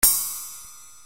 SP RIDE1.wav